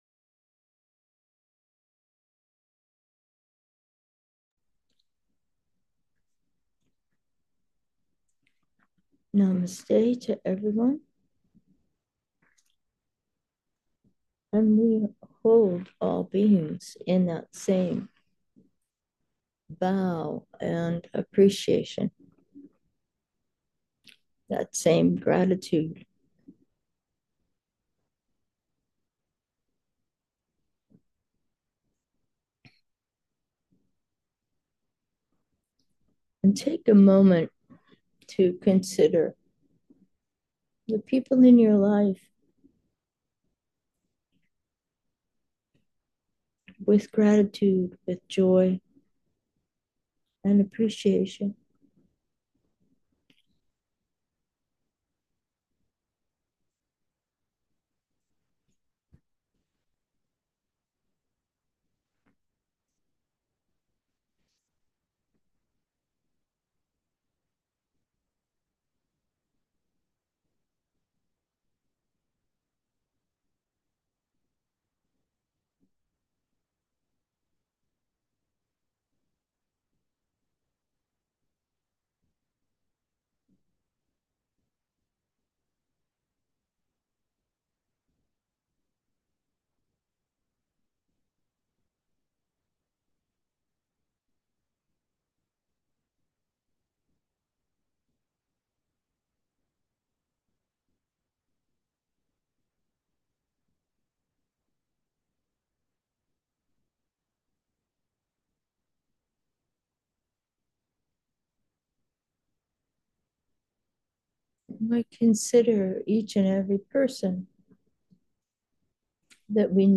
Meditation: with intention 7, genuine heart | Blazing Light, Love's Song